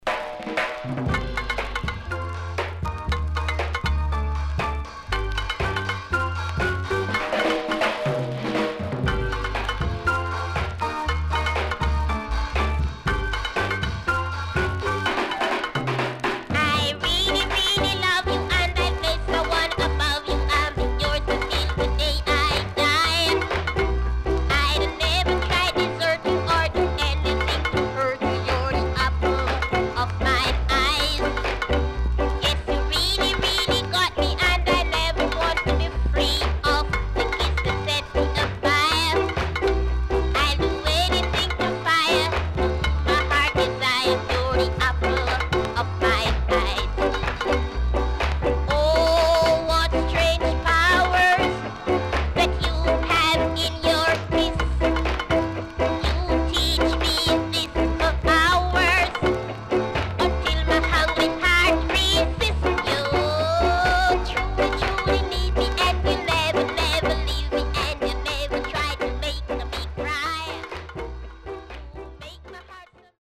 SKA
SIDE A:序盤少しチリノイズ入りますが落ち着きます。